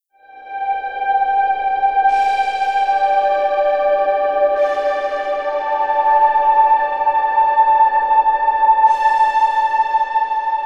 Synth 31.wav